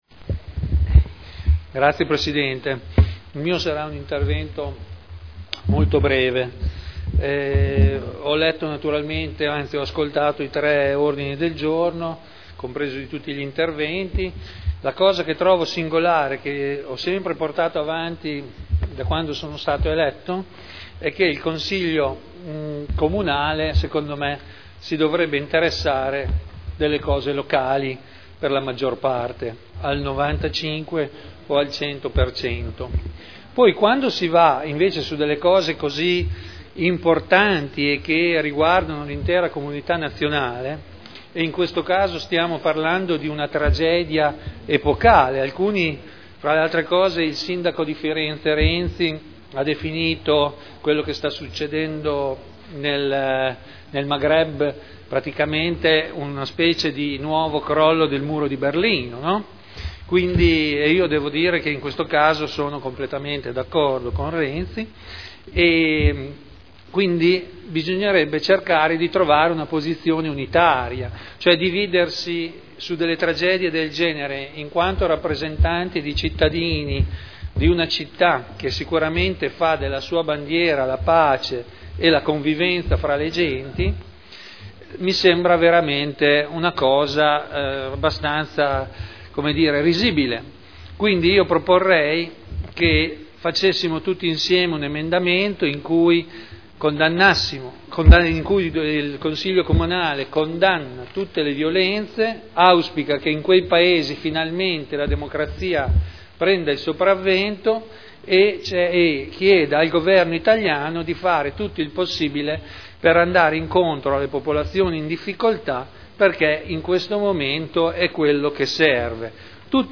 Seduta del 24/02/2011. Interviene sugli Ordini del Giorno riguardanti gli avvenimenti in Libia.